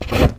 MISC Wood, Foot Scrape 05.wav